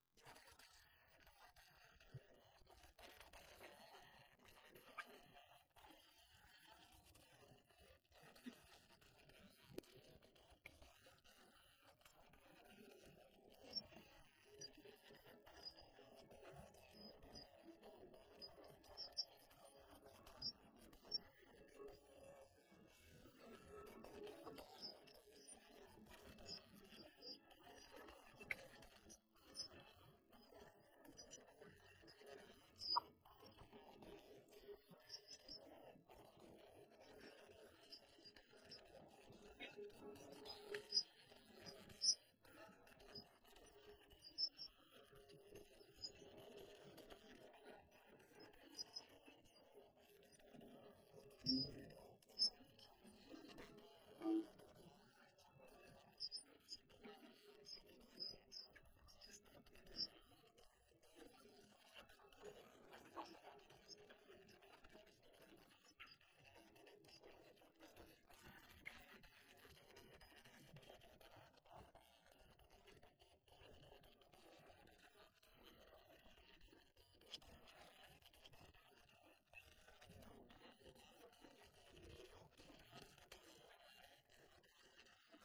audio_lon2_Instruments.wav